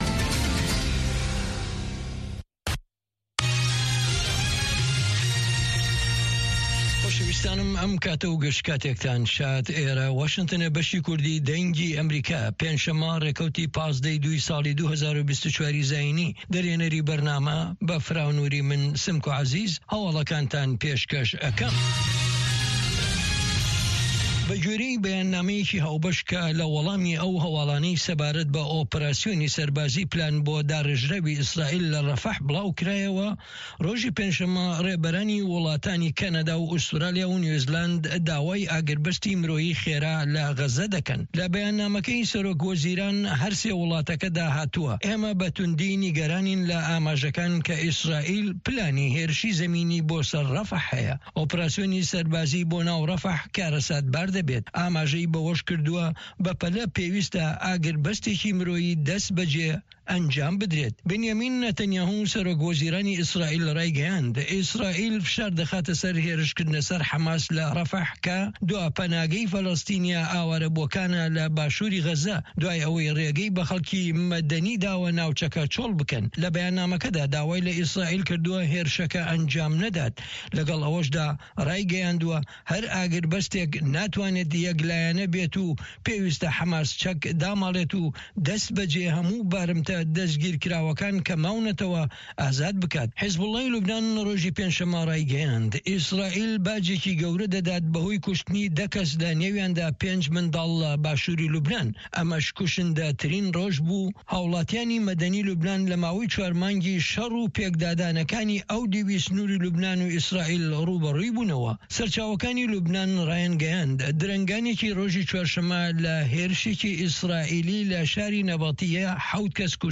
Nûçeyên Cîhanê 1